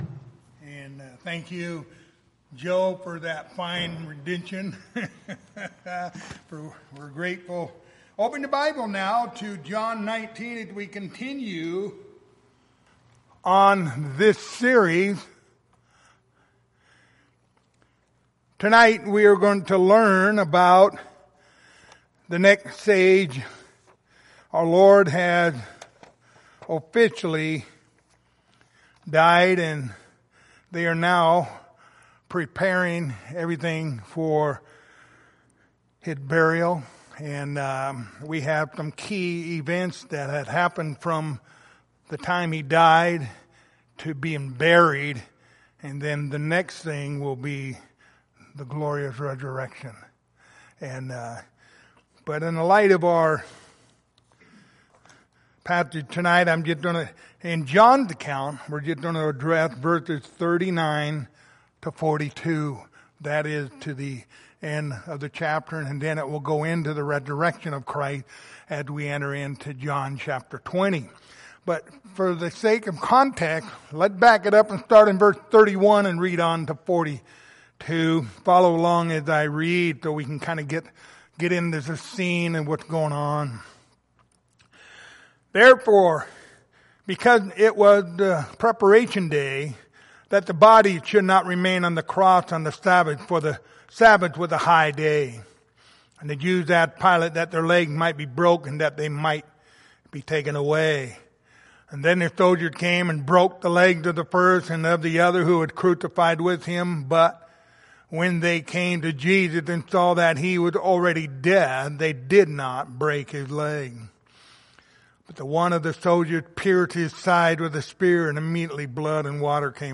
Passage: John 19:39-42 Service Type: Wednesday Evening